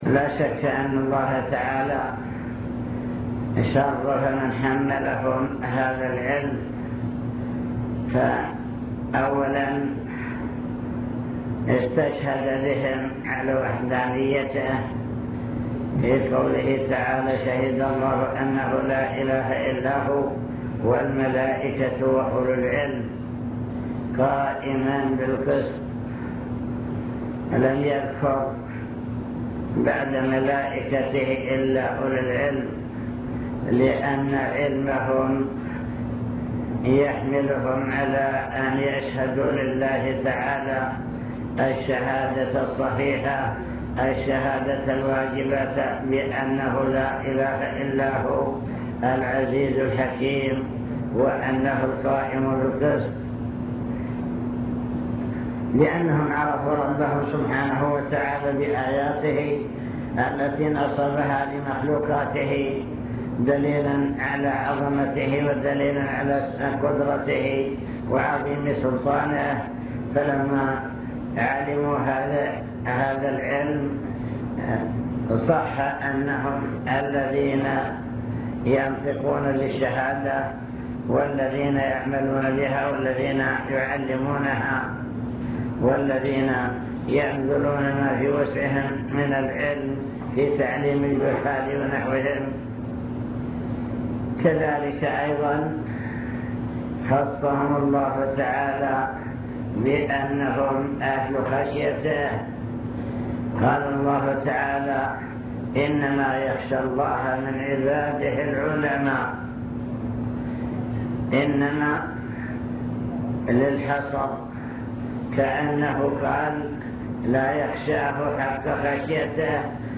المكتبة الصوتية  تسجيلات - لقاءات  لقاء إدارة التعليم